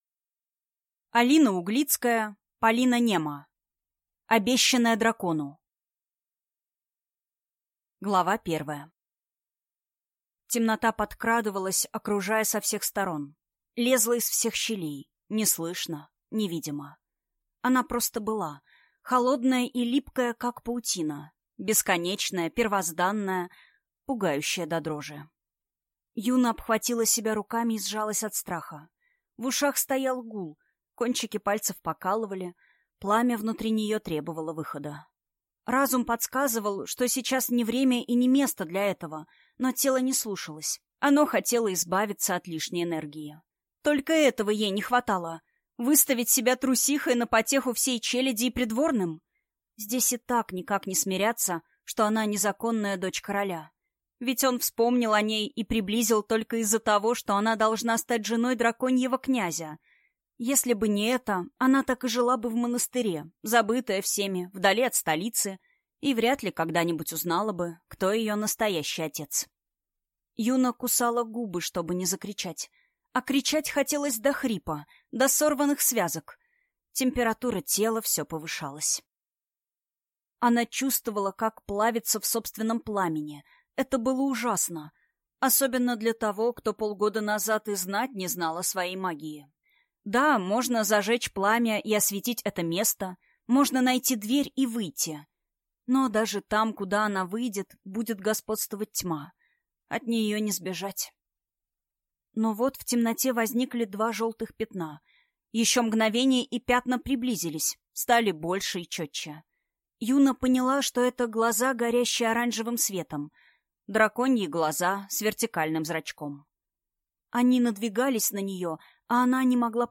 Аудиокнига Обещанная дракону | Библиотека аудиокниг